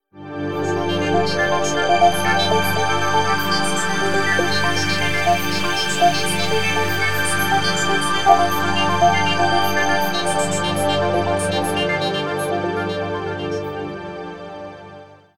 Pieza de música electrónica
melodía
moderno